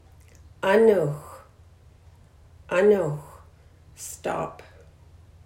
These words and greetings in Hul’q’umi’num’ were recorded by a district elder and are offered as a way to learn and practice the language.